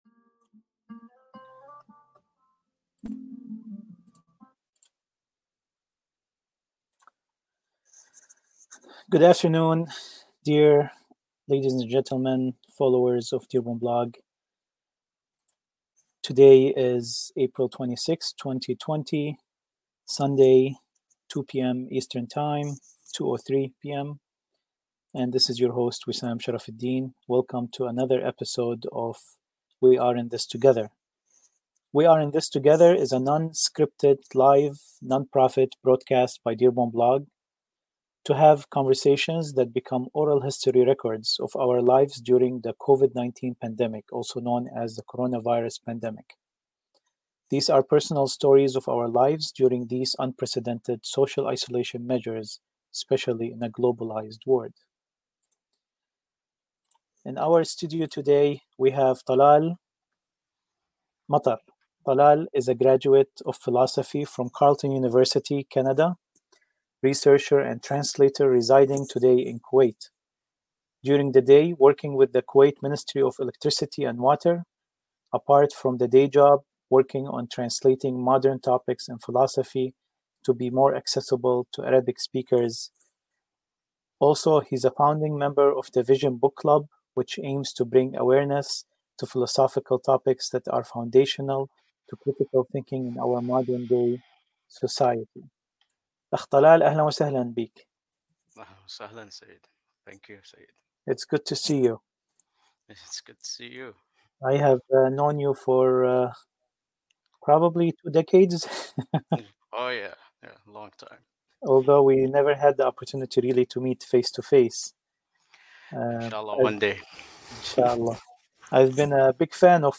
a non-scripted, live, non-profit, broadcast